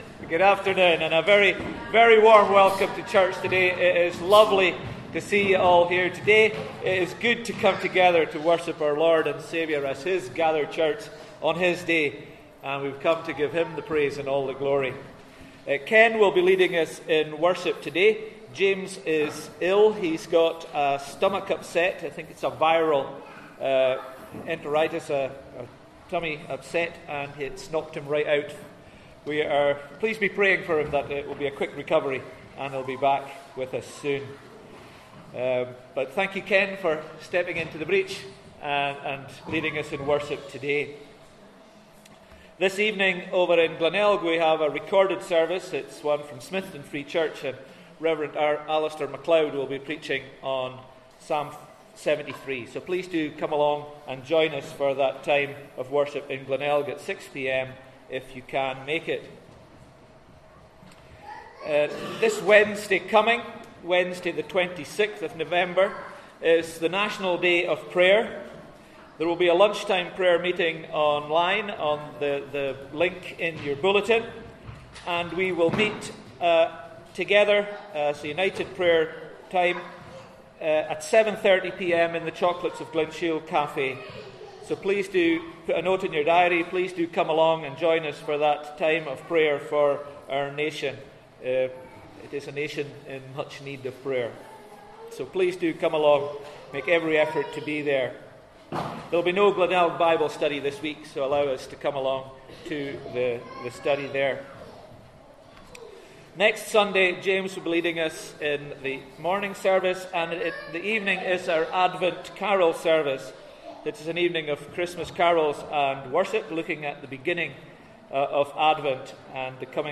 A Sermon of 8 Words